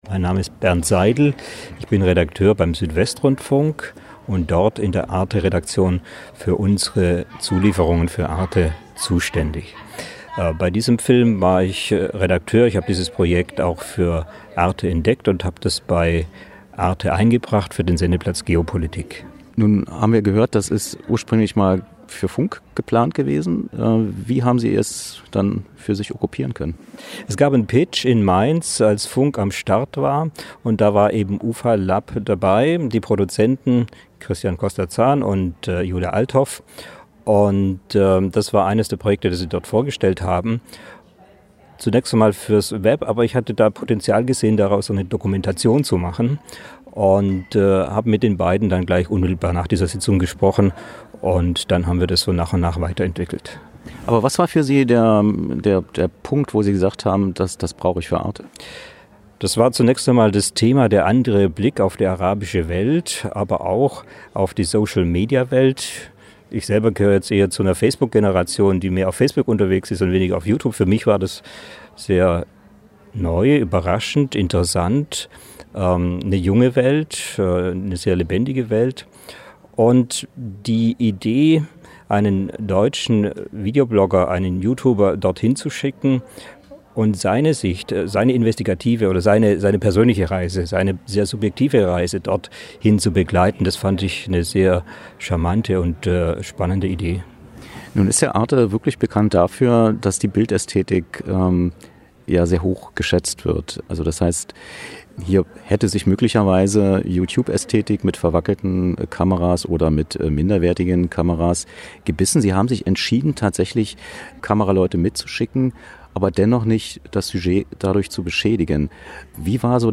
Was: Interview zur ARTE-Doku „Follow Me: Arabische Videostars“
Wo: Berlin, Kino Babylon